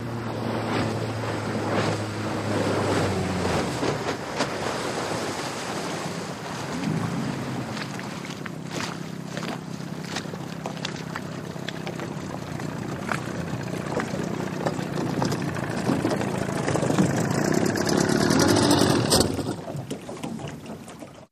20 hp Johnson Boat Pull Up Shut Off